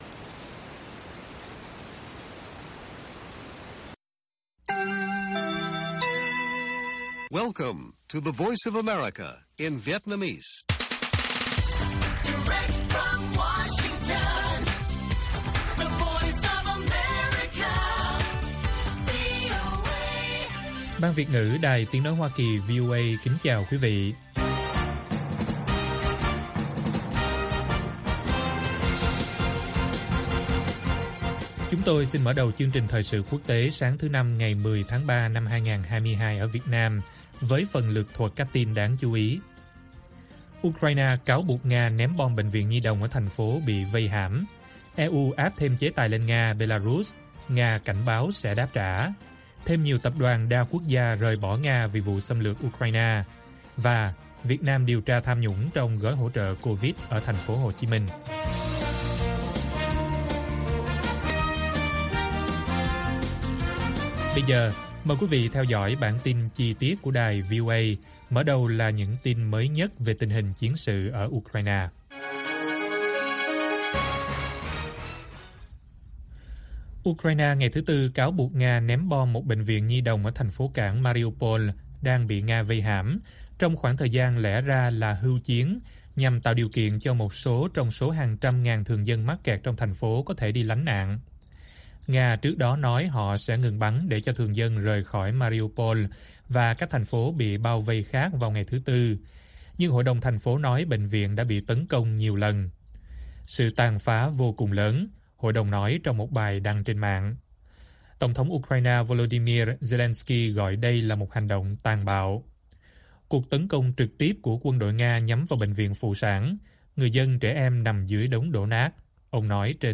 Ukraine cáo buộc Nga ném bom bệnh viện nhi đồng - Bản tin VOA